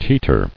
[tee·ter]